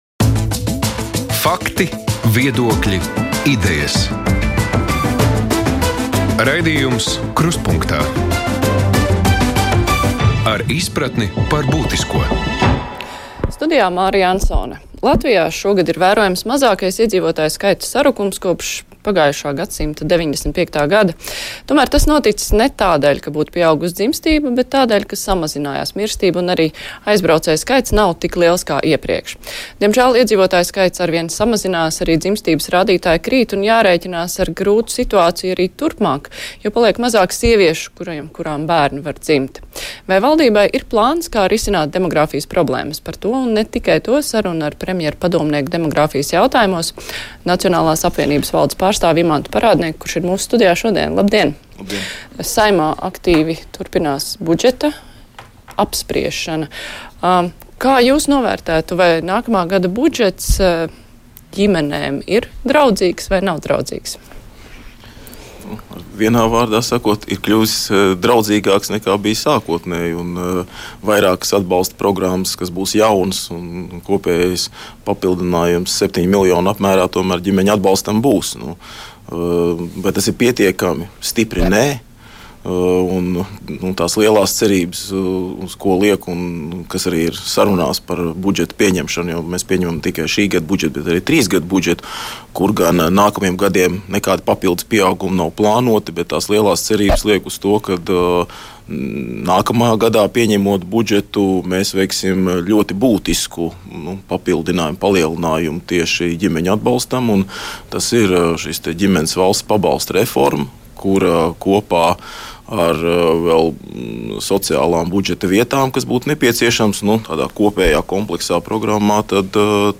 Vai valdībai ir plāns, kā risināt demogrāfijas problēmas – par to saruna ar premjera padomnieku demogrāfijas jautājumos, Nacionālās apvienības valdes pārstāvi Imantu Parādnieku.